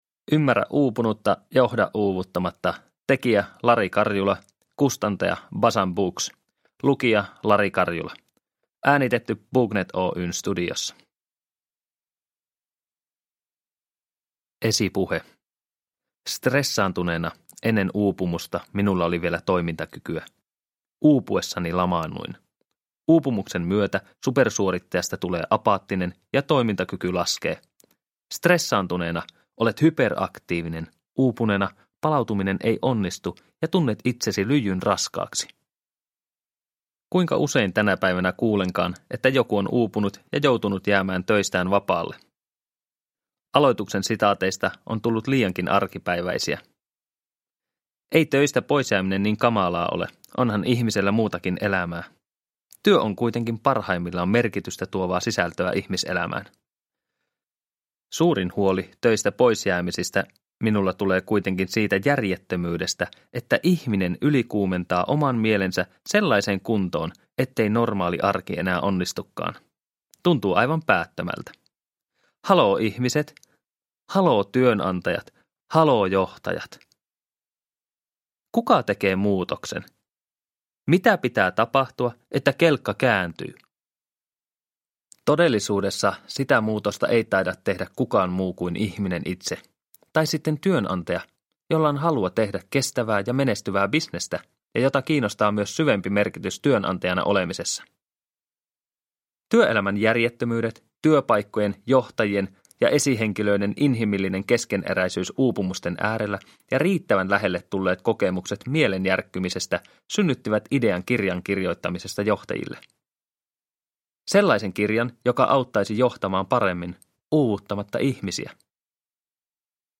Ymmärrä uupunutta, johda uuvuttamatta – Ljudbok